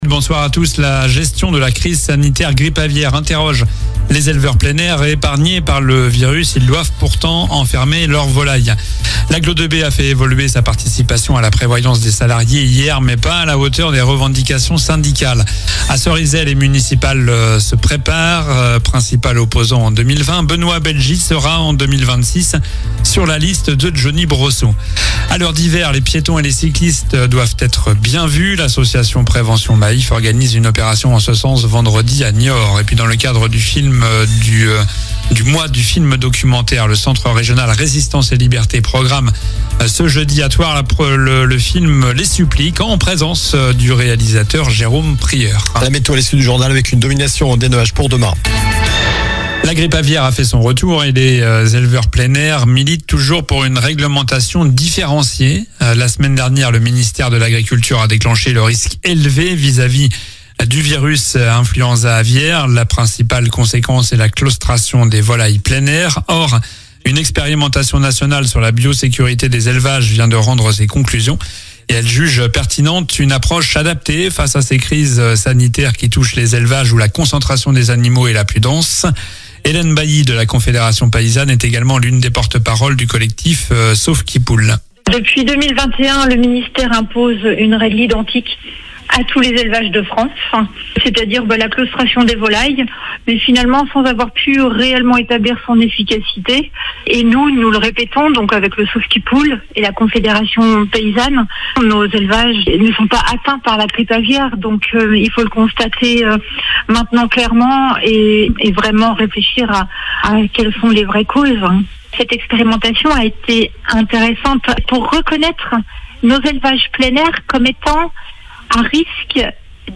Journal du mercredi 5 novembre (soir)